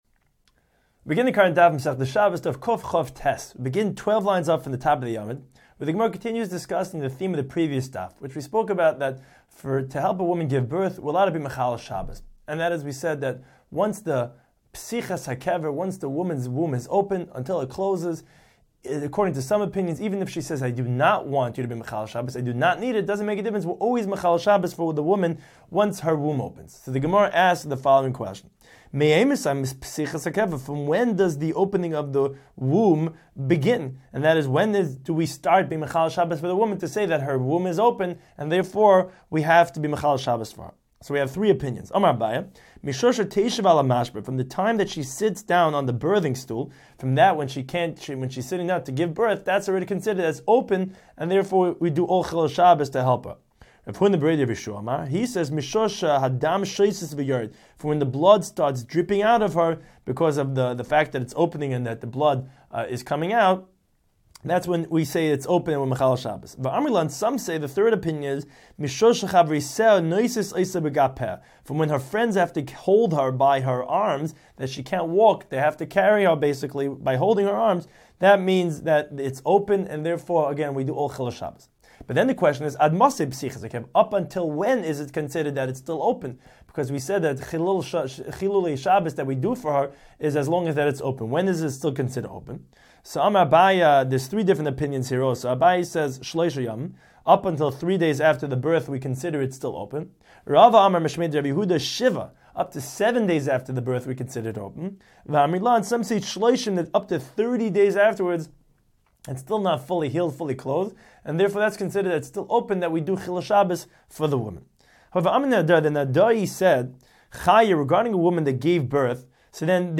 Daf Hachaim Shiur for Shabbos 129